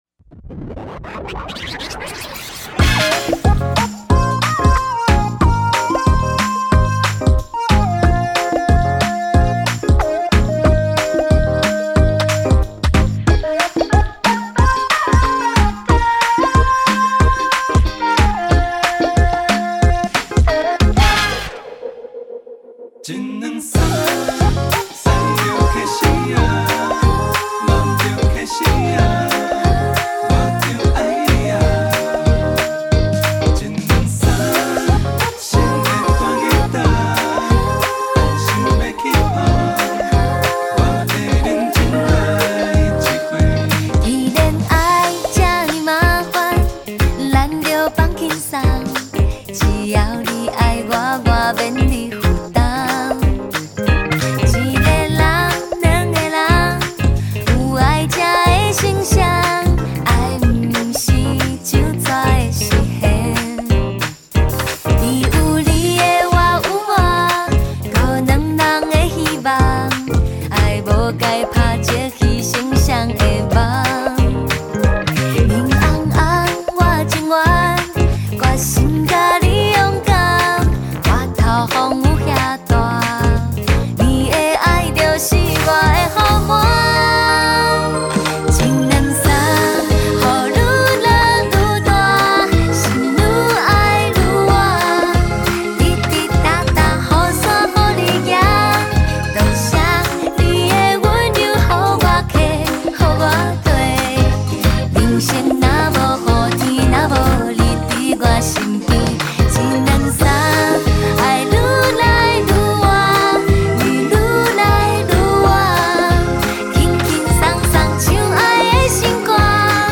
台語歌壇新傳奇女聲  歷經13年淬煉真實力感動冠軍台語專輯